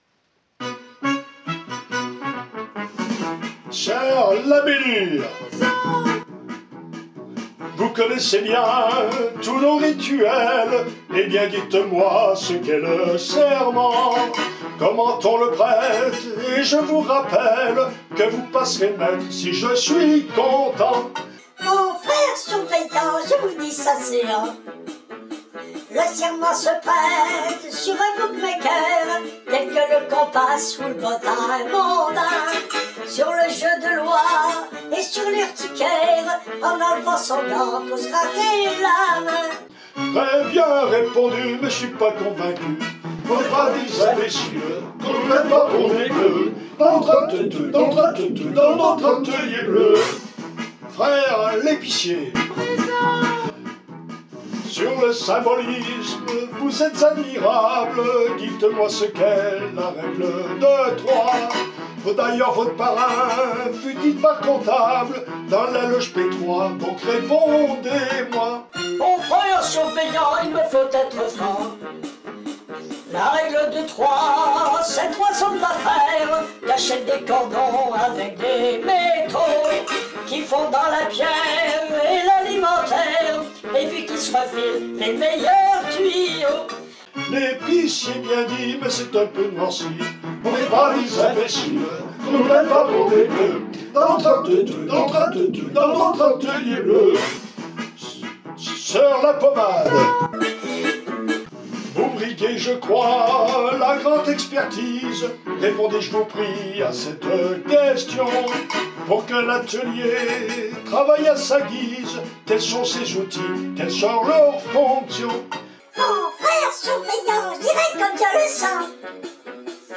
Enregistrement fantaisiste